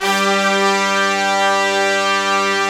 G3 POP BRASS.wav